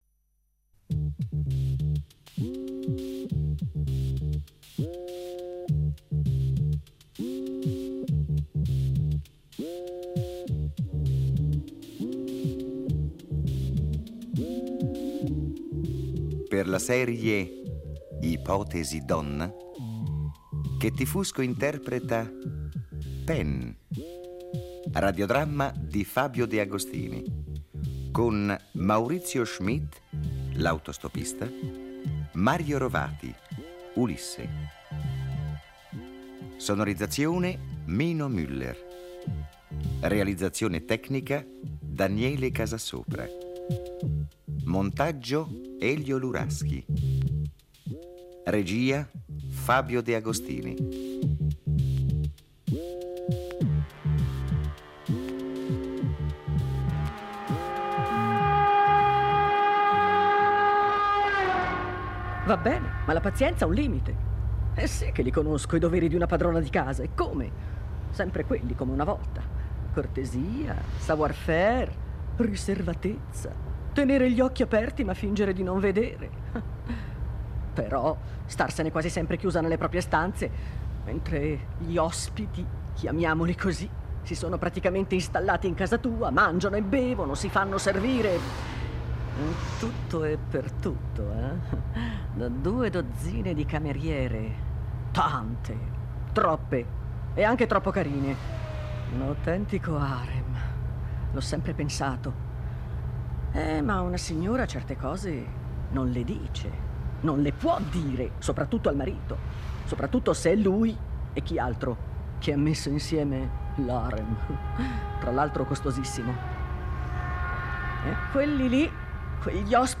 Nove storie interpretate magistralmente